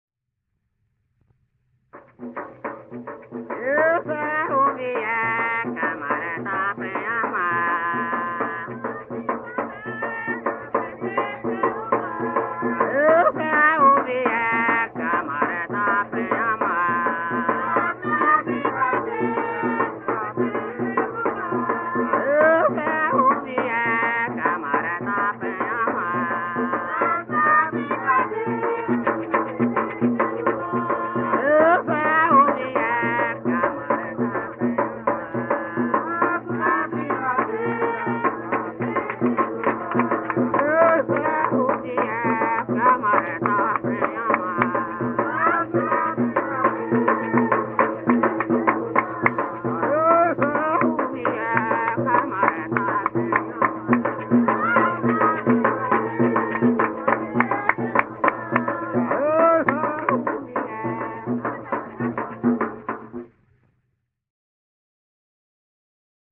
Coco solto